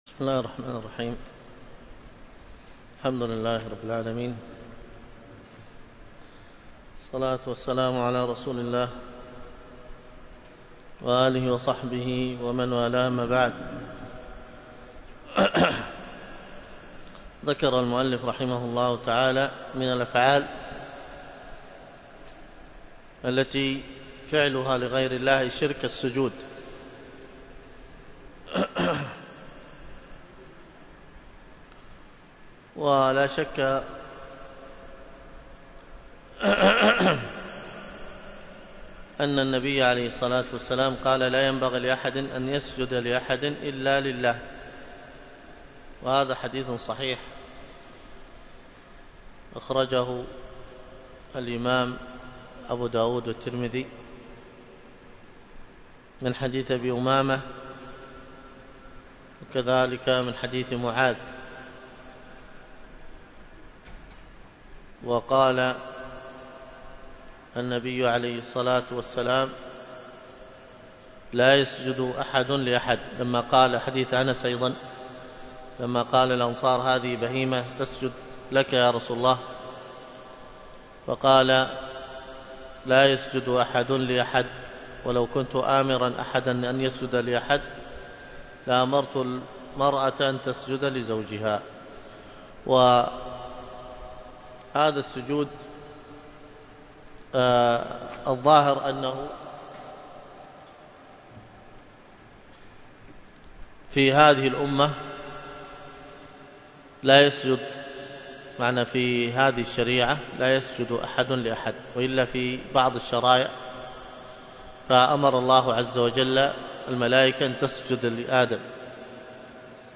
المحاضرة
والتي كانت بمسجد التقوى بدار الحديث بالشحر عصر يوم الجمعة